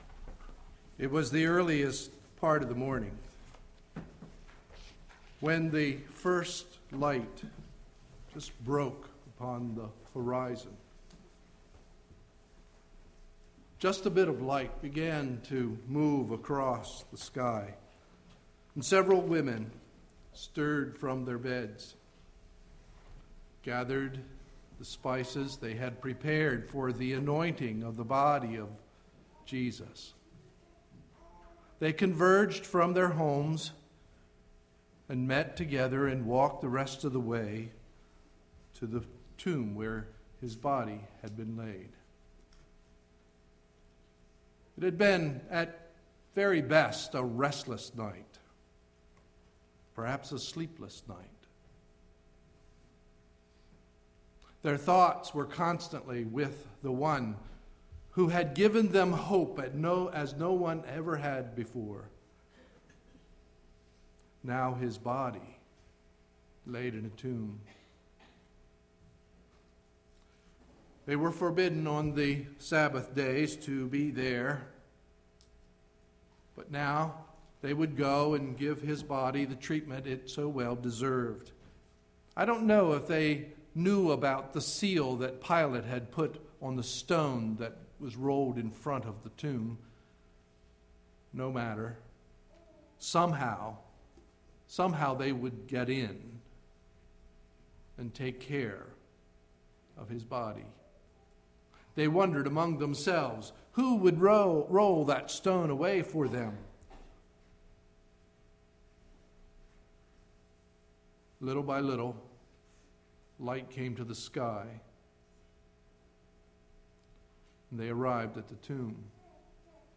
Sunday, April 24, 2011 – Morning Message